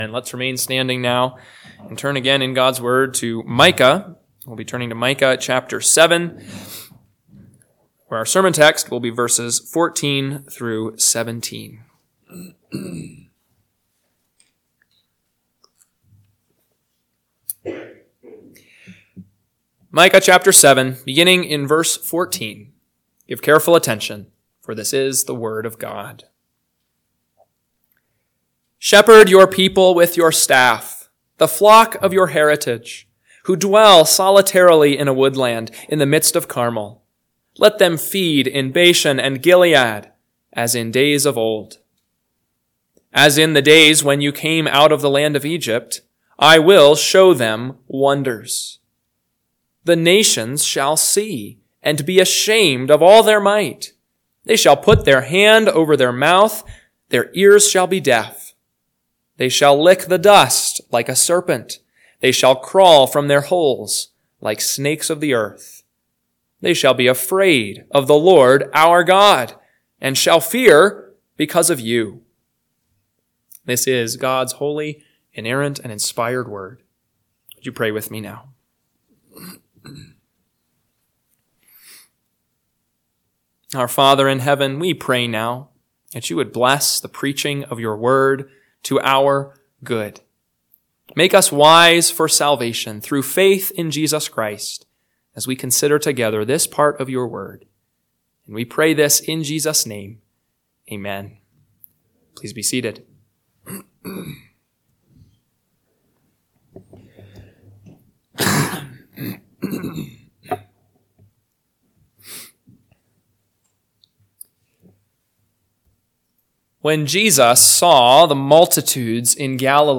PM Sermon – 12/22/2024 – Micah 7:14-17 – Northwoods Sermons